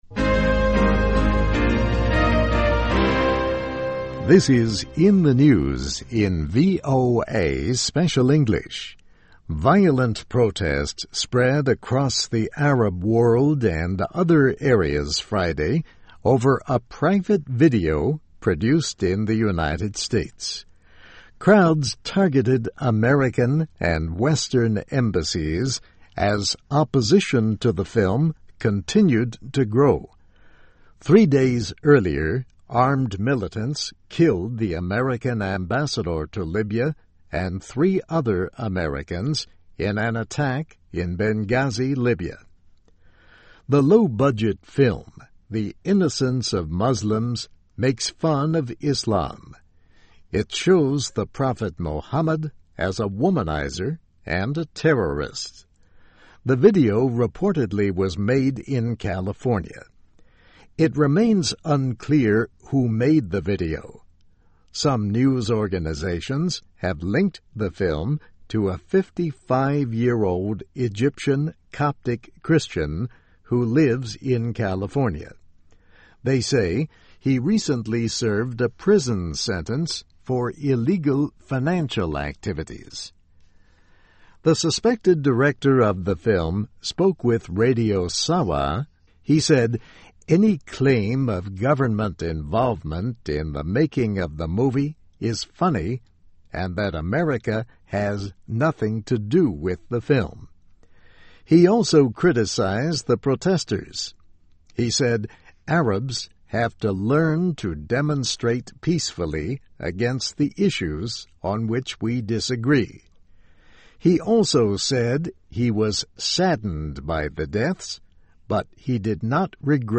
VOA慢速英语, In the News, 针对反伊斯兰影片的示威蔓延开来